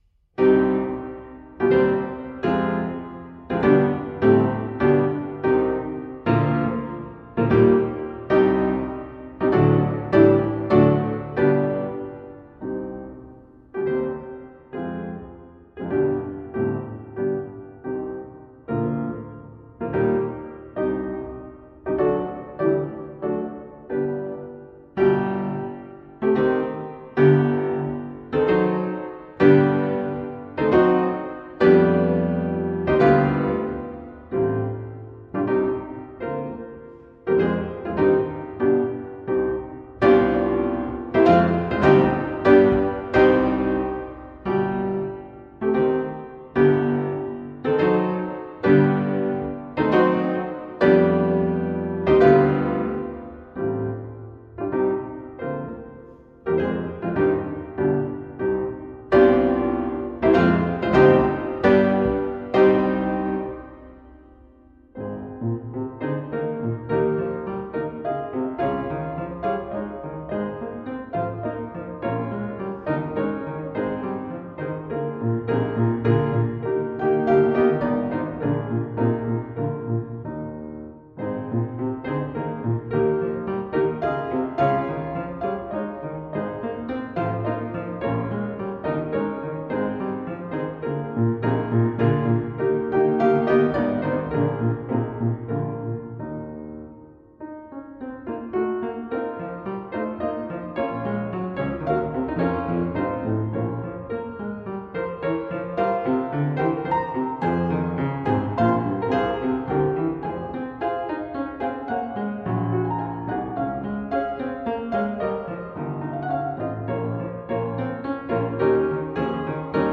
Style: Classical
Pedal Piano